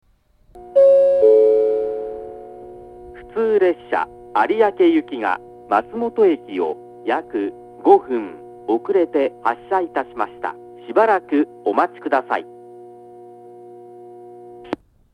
１番線下り松本駅発車案内放送 普通有明行（５分延）の放送です。
hitoichiba-1bannsenn-kudari-matsumoto-hassha.mp3